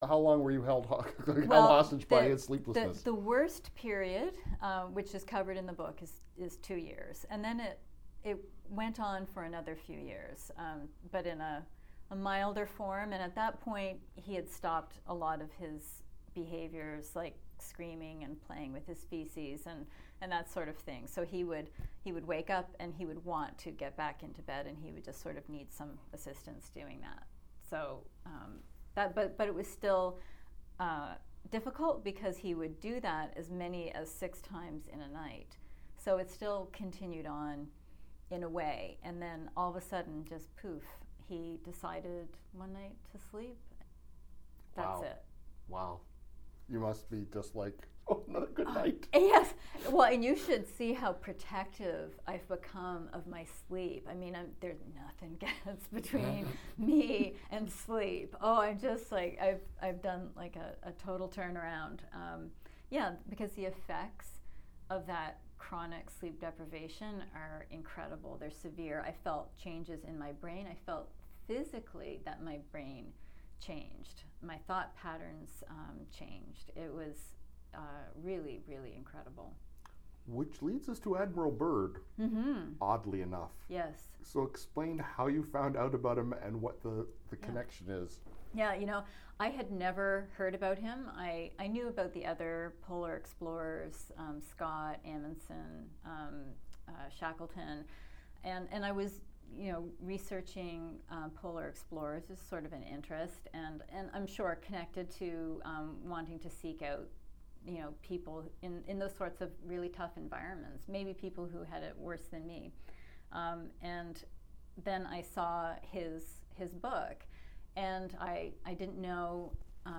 Recording Location: Toronto
Type: Interview
128kbps Stereo